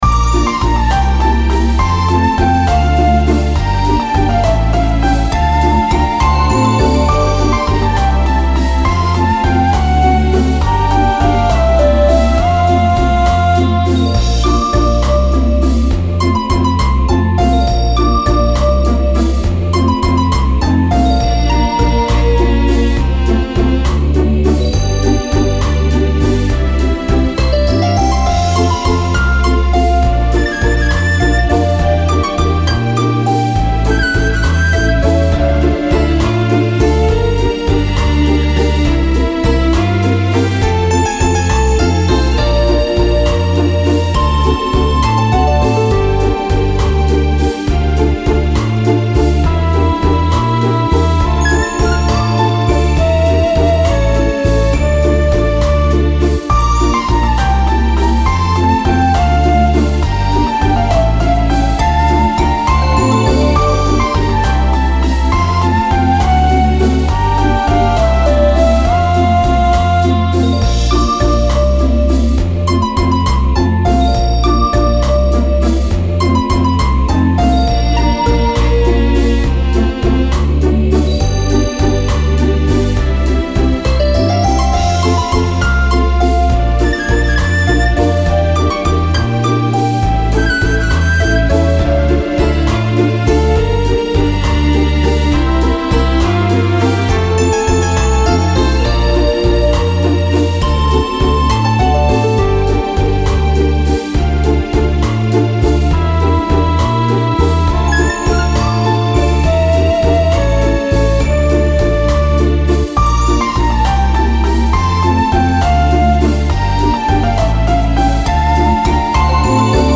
Музыка без слов для детского праздника